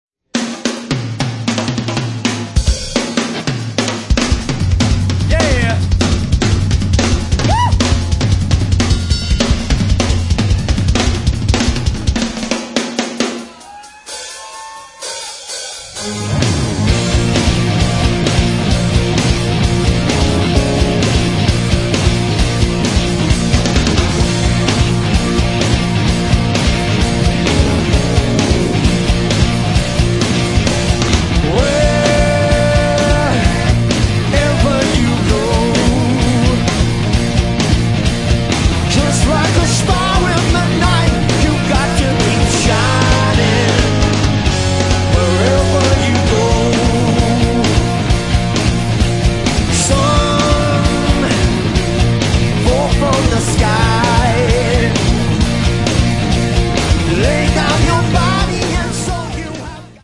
Рок
Двойной концертник рок-легенды.